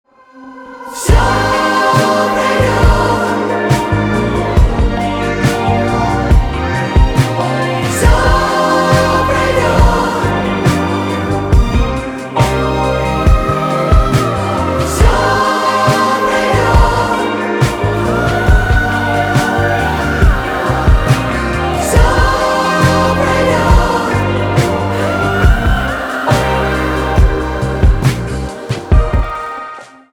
поп
красивые , чувственные
гитара , барабаны